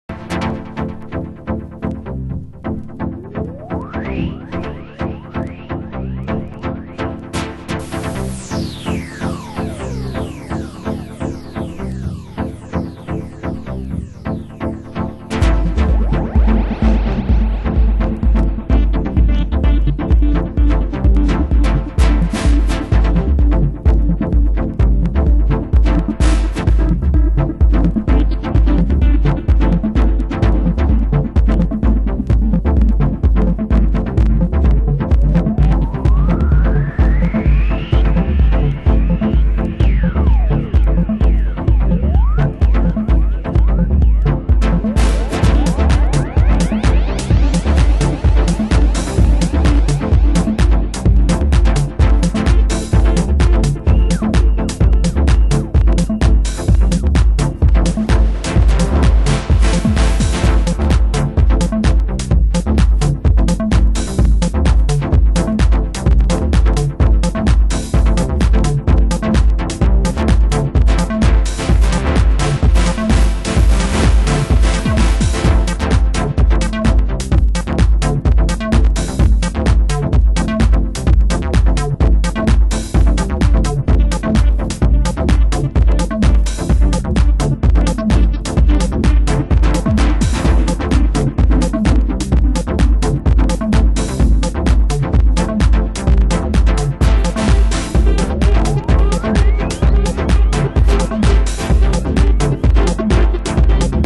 盤質：少しチリノイズ有